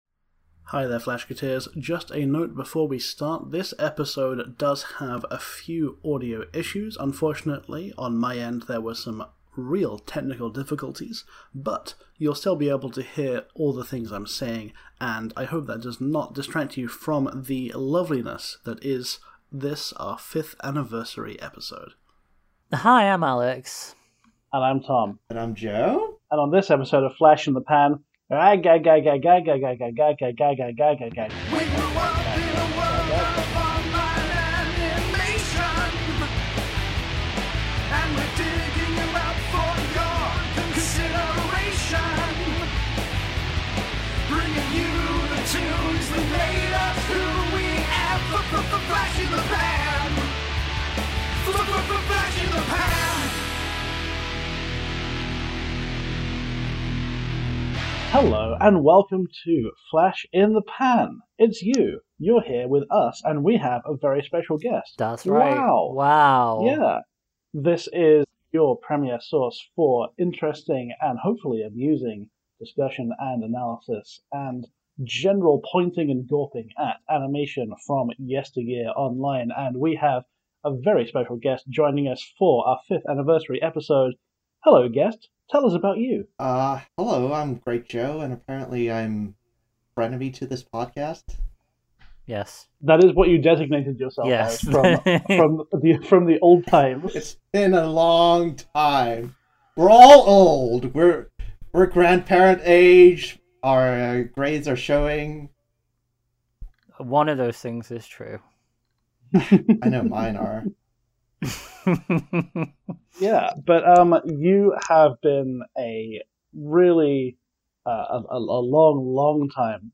Technical difficulties and emotional reminiscences and very kind emails fill out this lovely, lovely episode.